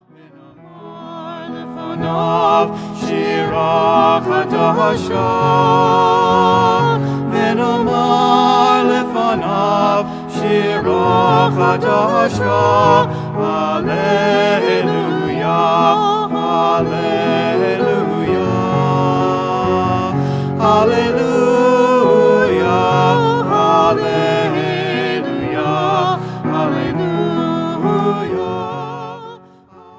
• Choral
CJM (Contemporary Jewish Music)